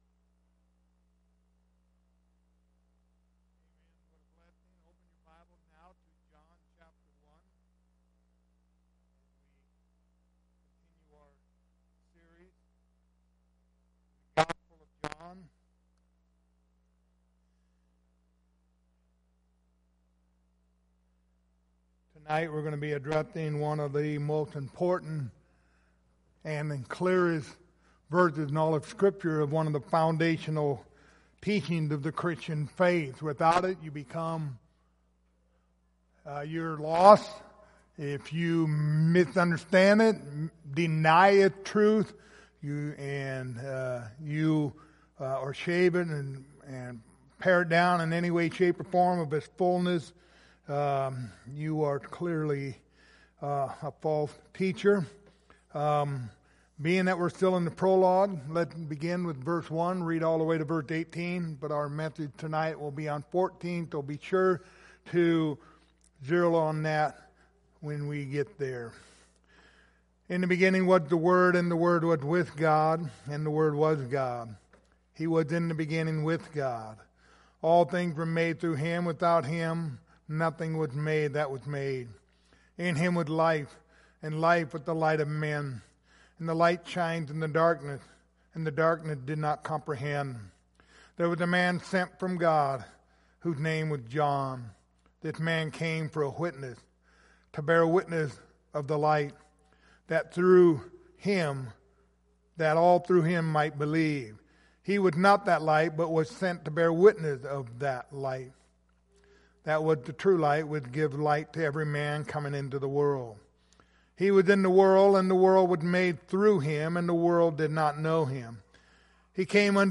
The Gospel of John Passage: John1:14 Service Type: Wednesday Evening Topics